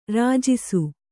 ♪ rājisu